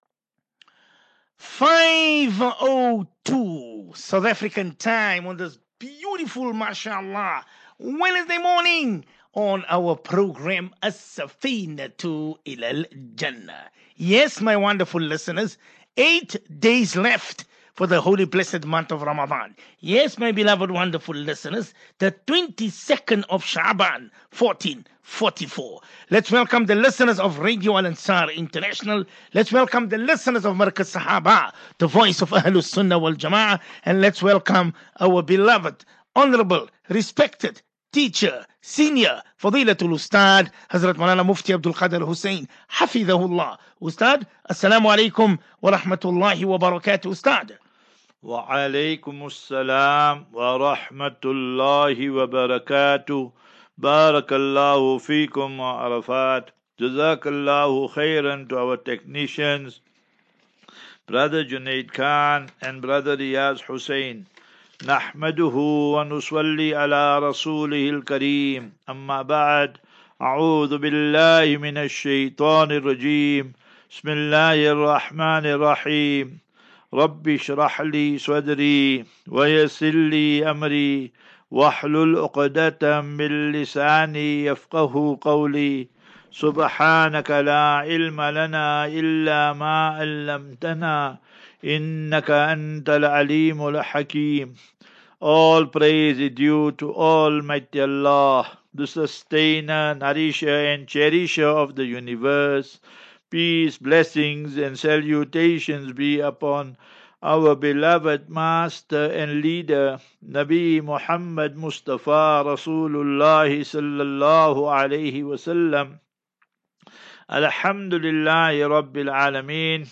View Promo Continue Install As Safinatu Ilal Jannah Naseeha and Q and A 15 Mar 15 Mar 23 Assafinatu